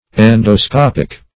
Search Result for " endoscopic" : Wordnet 3.0 ADJECTIVE (1) 1. of or relating to endoscopy ; The Collaborative International Dictionary of English v.0.48: endoscopic \en`do*scop"ic\, a. (Med.)
endoscopic.mp3